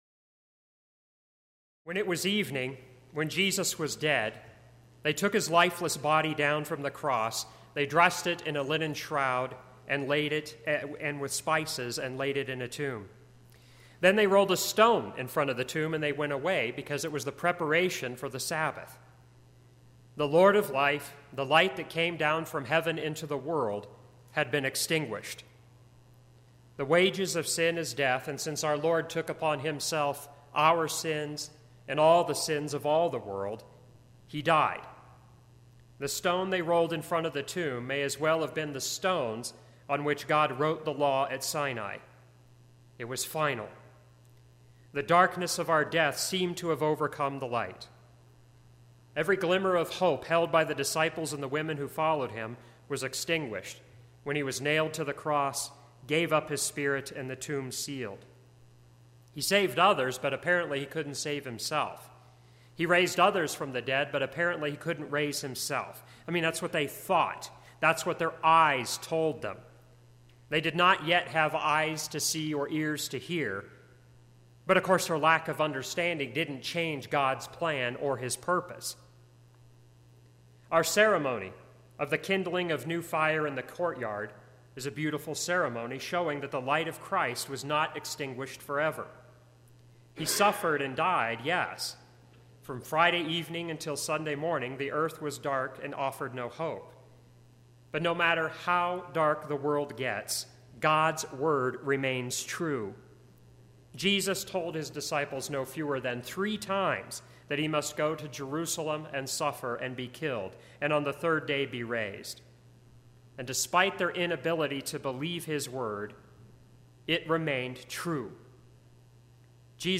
Saturday Easter Vigil Service
Sermon – 4/15/2017